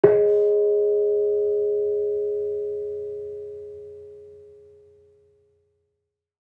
Gamelan Sound Bank
Kenong-resonant-G#3-f.wav